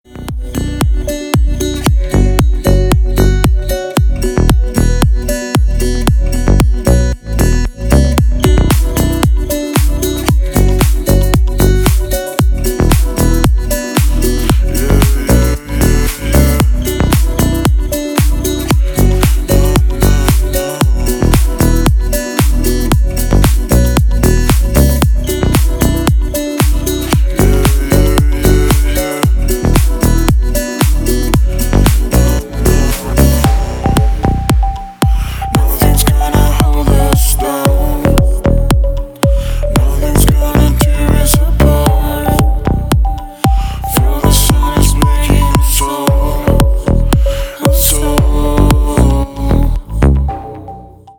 • Песня: Рингтон, нарезка
• Категория: Красивые мелодии и рингтоны